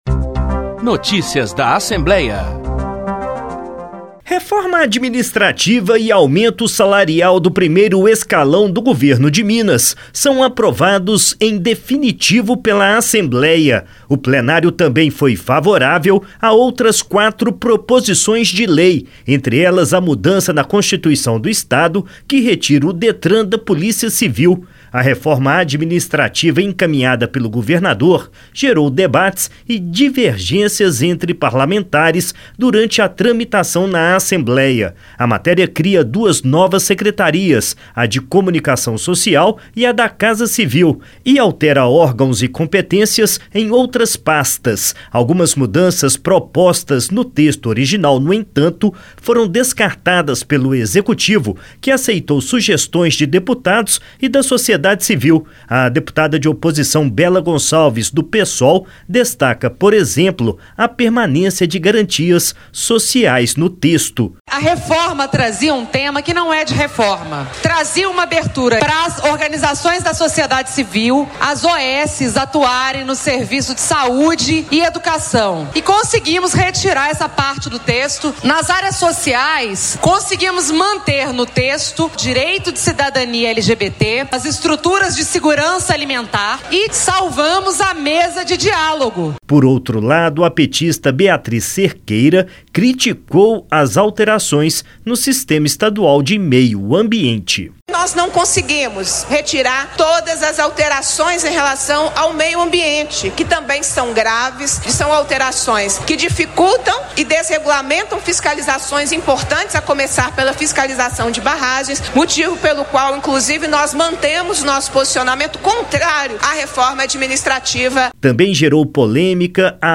Notícias da Assembleia